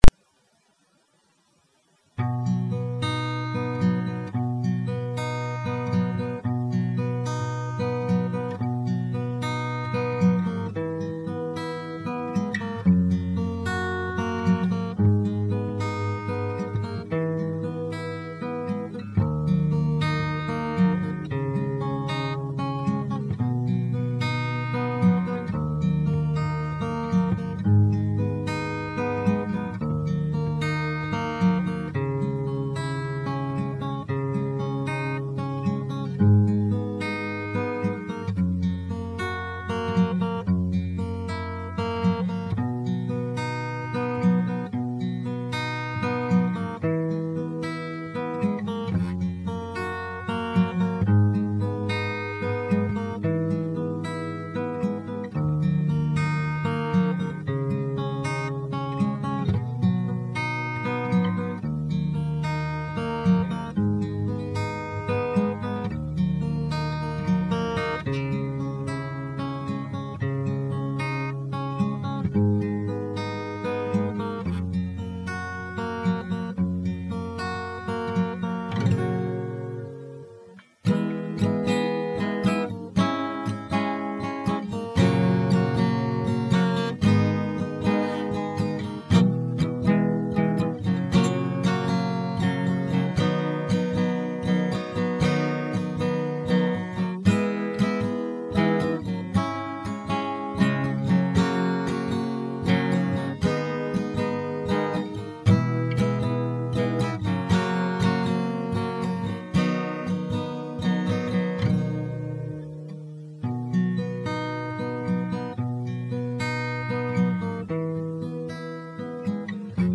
=====这有伴奏，歌词有修改=========
欢迎大家来试试，这个歌难度比较小，属于容易上口型，呵呵。